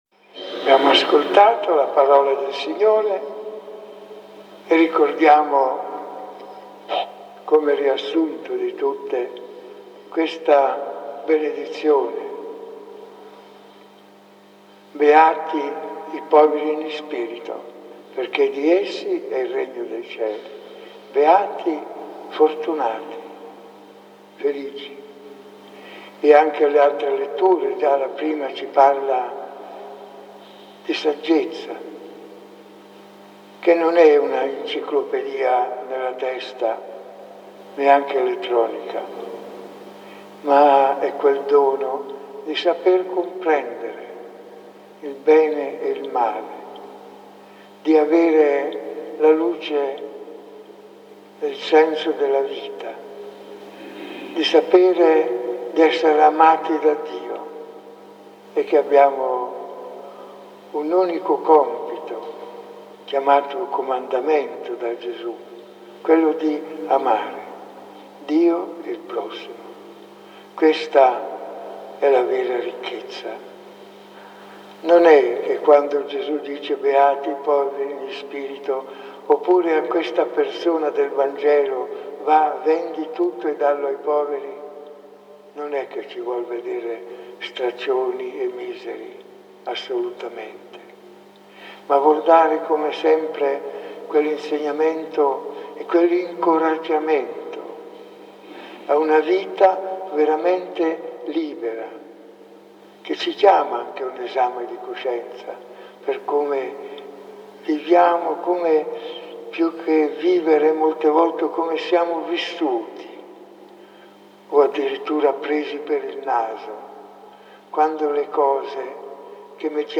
Omelia di Domenica 10 Ottobre 2021 - Beati i poveri nello spirito